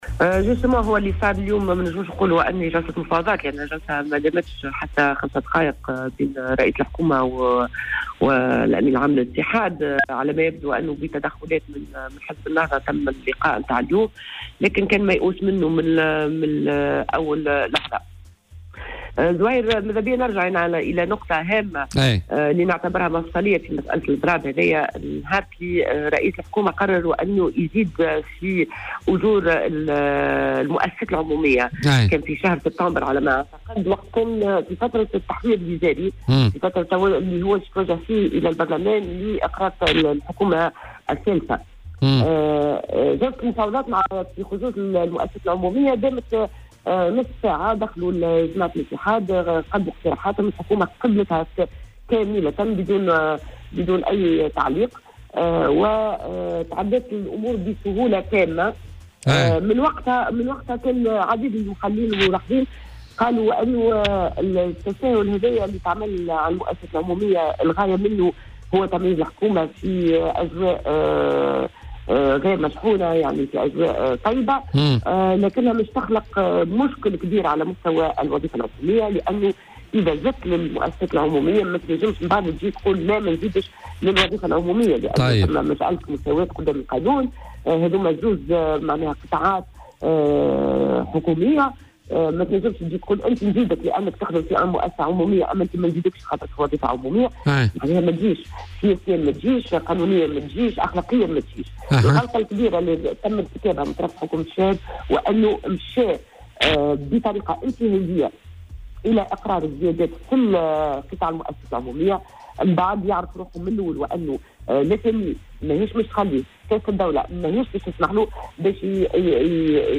وأضافت في مداخلة هاتفية مع "بوليتيكا" أن الحكومة ارتكبت بطريقة انتهازية هذا الخطأ حيث تمت المفاوضات بالقطاع العام دون تعطيل خاصة وأنها تزامنت مع التحوير الوزاري الأخير. وتوقعت ذات المتحدثة تعطل كل مرافق الدولة غدا، محذرة من انزلاق الاضراب إلى دائرة العنف.